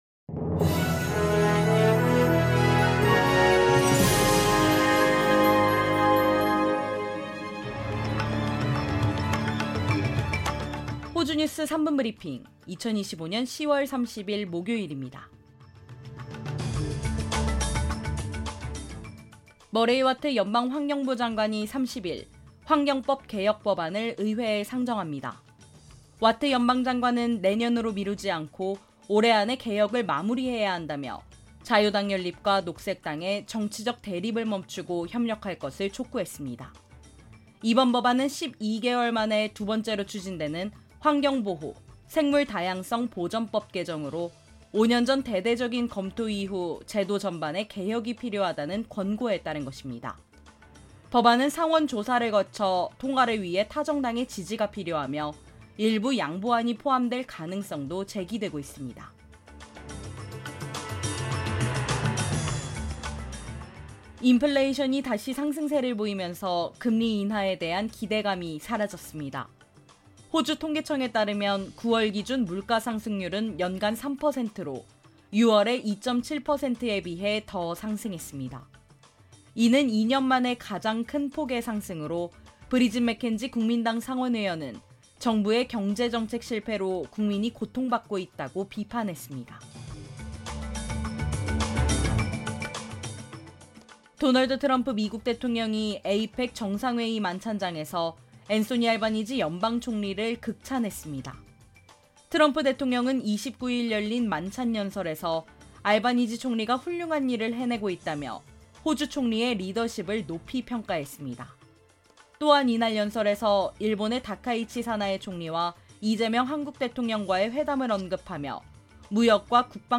호주 뉴스 3분 브리핑: 2025년 10월 30일 목요일